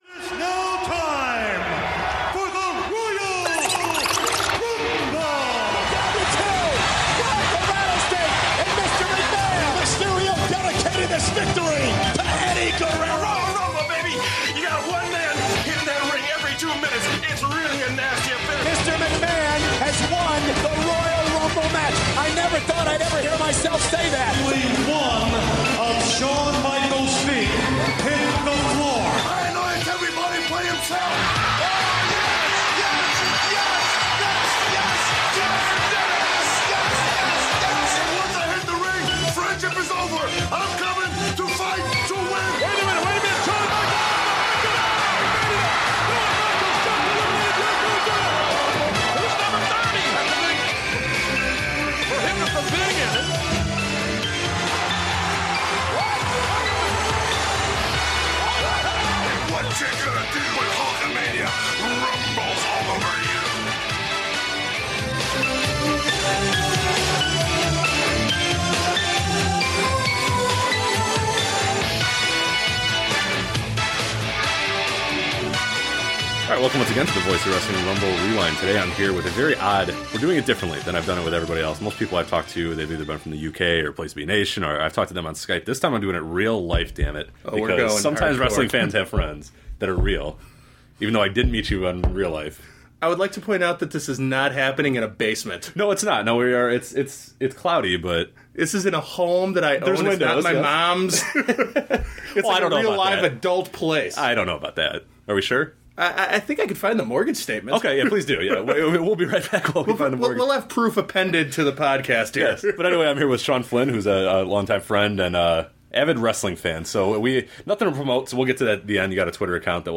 LIVE in studio